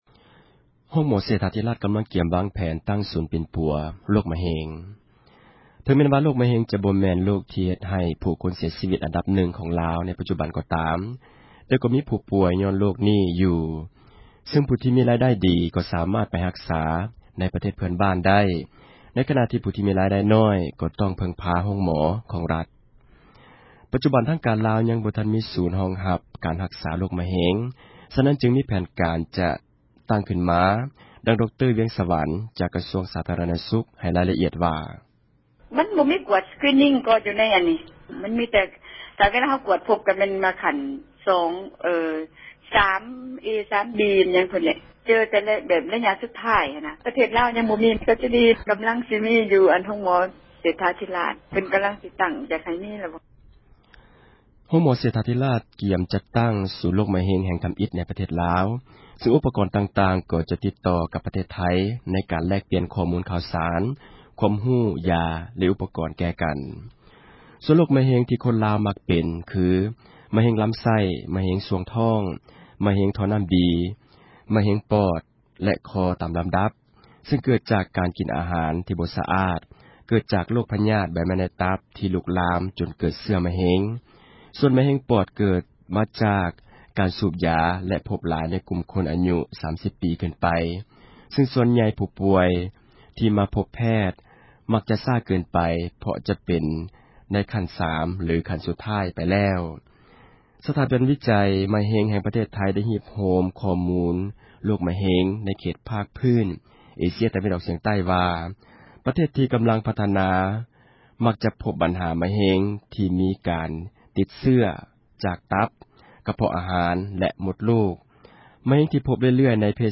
ຣາຍງານ